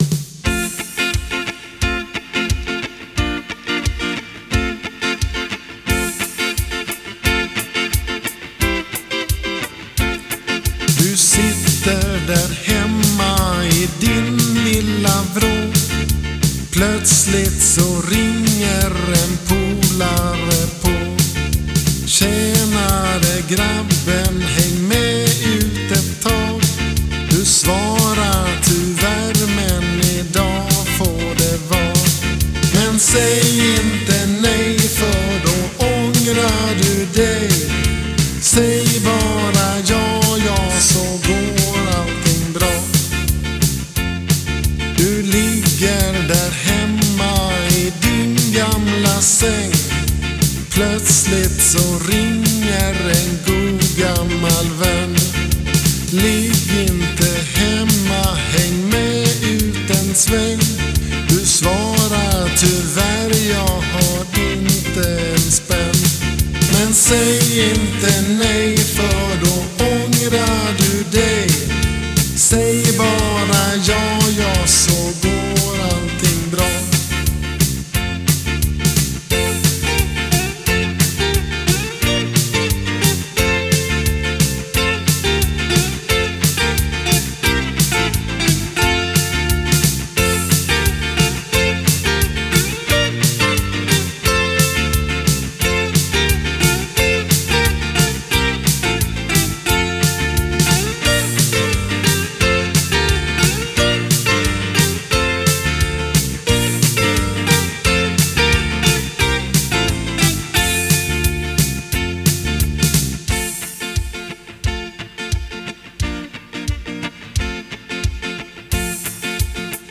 Musik: Ännu en produkt av min 3-ackordsperiod.